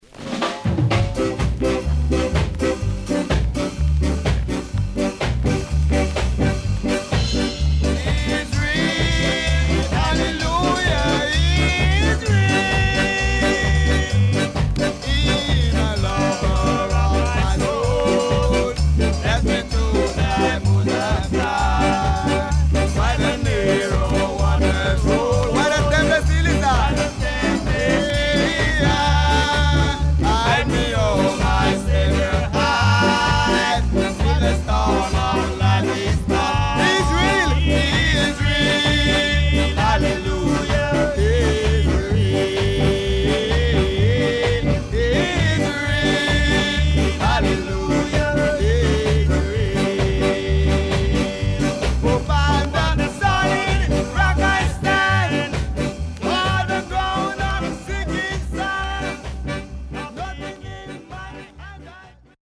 SIDE A:ヒスノイズ入ります。